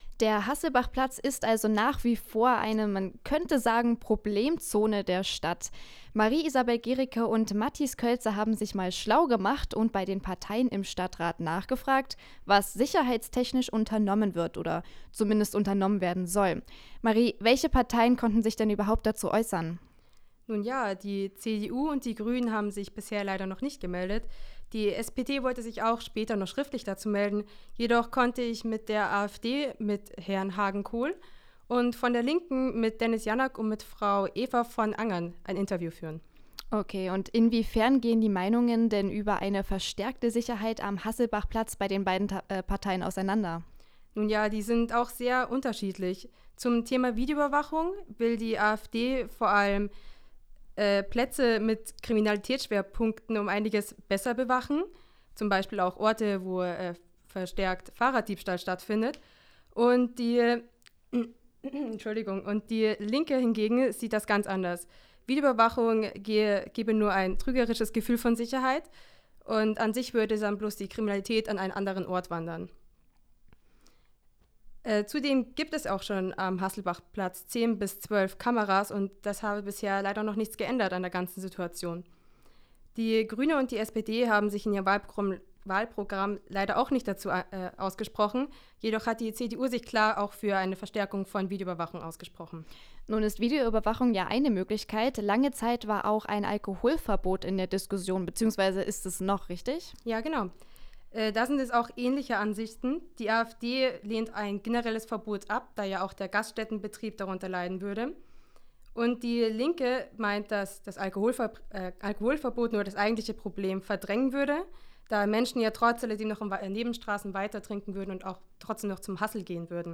KollegInnen-Gespräch-Hasselbachplatz.wav